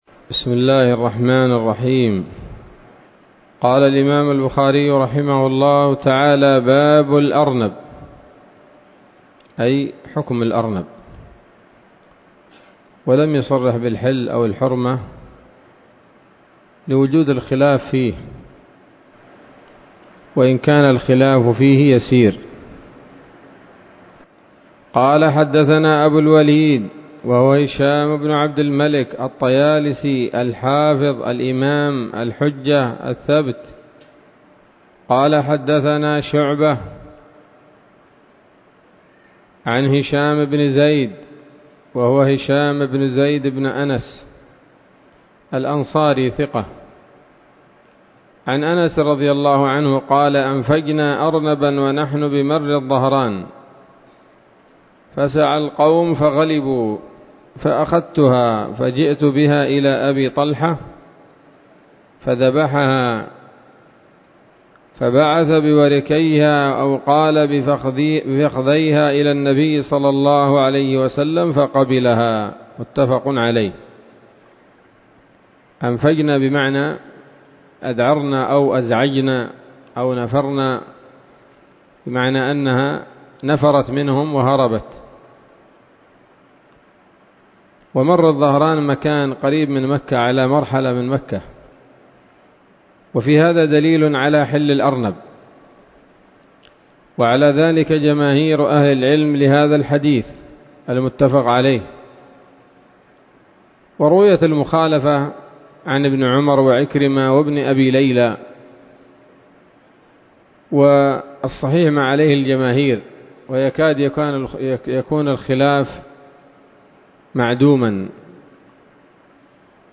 الدرس الثامن والعشرون من كتاب الذبائح والصيد من صحيح الإمام البخاري